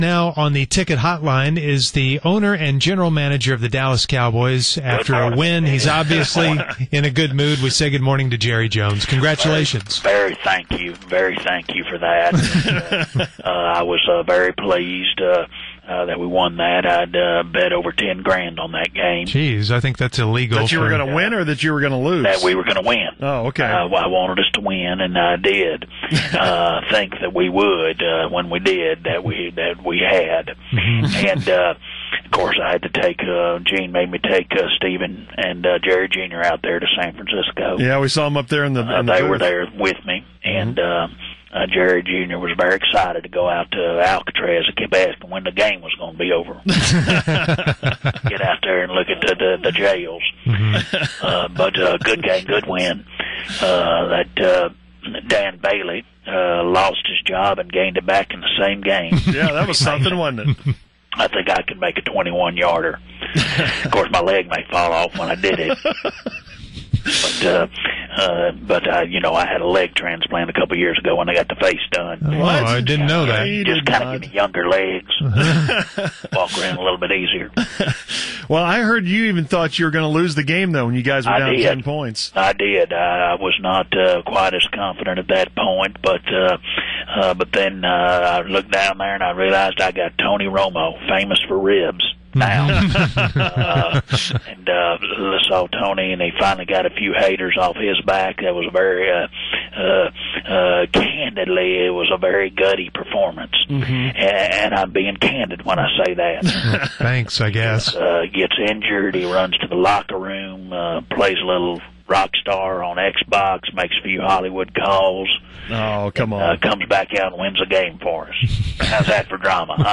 Jerry stumbles around his words and Irvin gets stuck on his words for this lovely conversation.